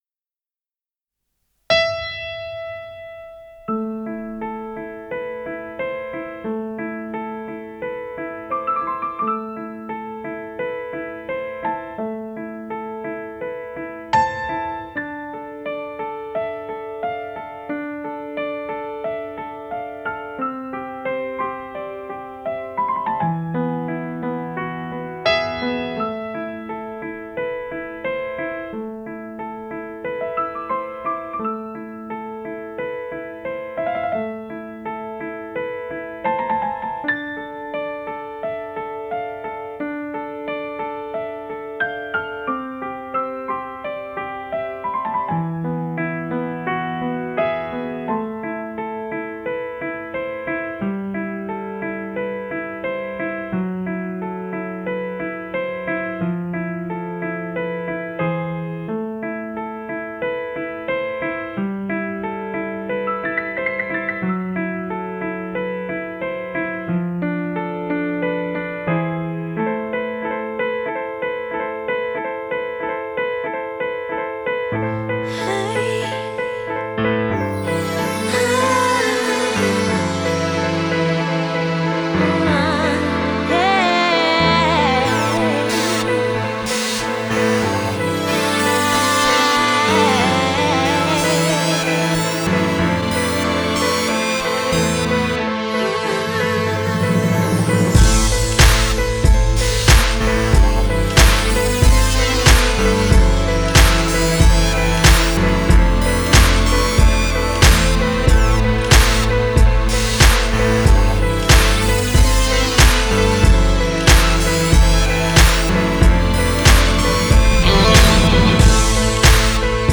R&B, Hip Hop, Funk, Soul and Rock
talk-box skills that only get better by the song